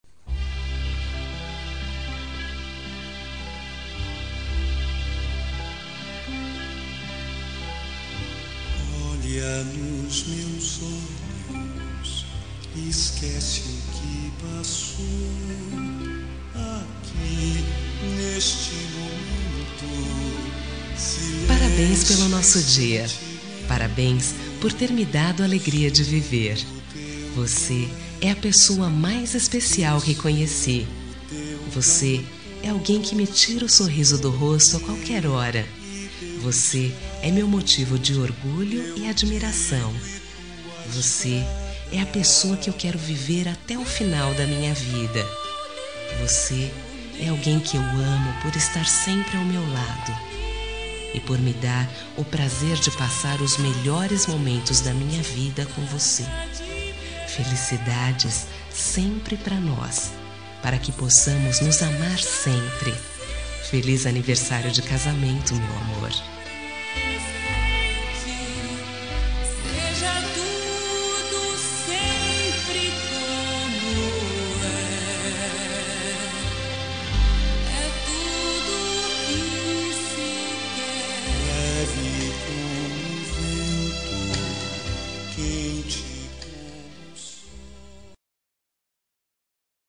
Telemensagem de Aniversário de Casamento Romântico – Voz Feminina – Cód: 1304